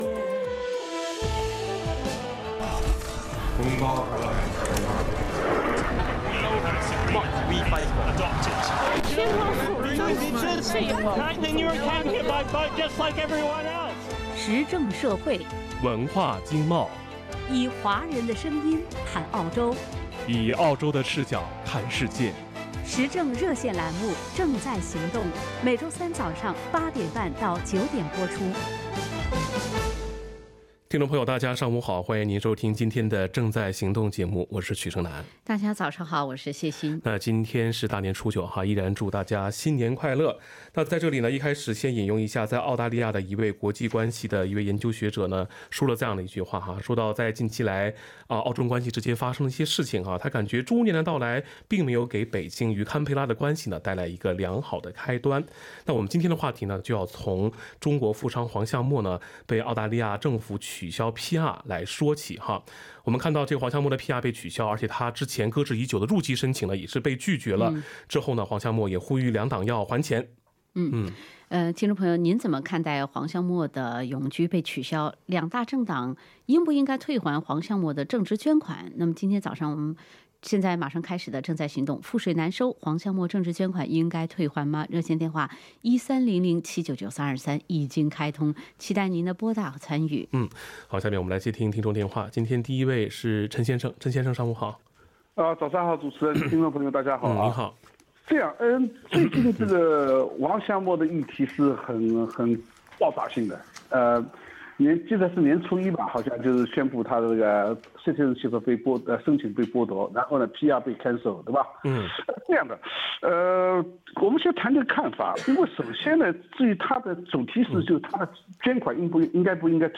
action_talkback.mp3